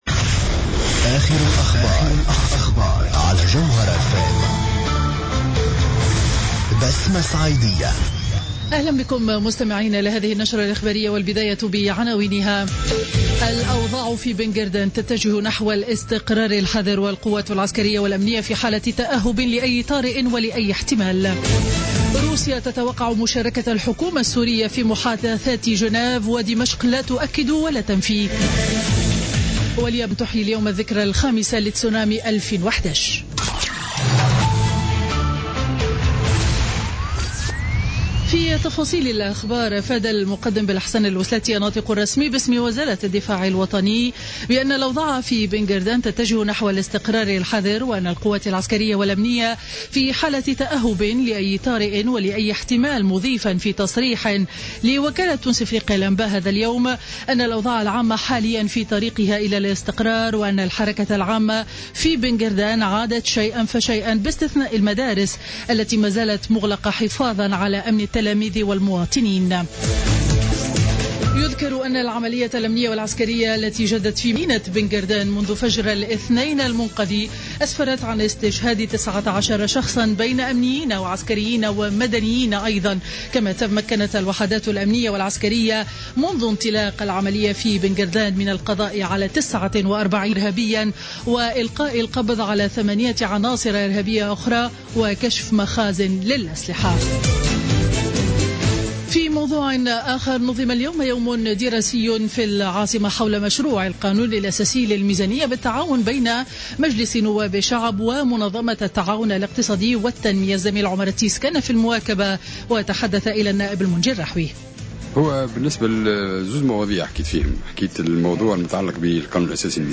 نشرة أخبار منتصف النهار ليوم الجمعة 11 مارس 2016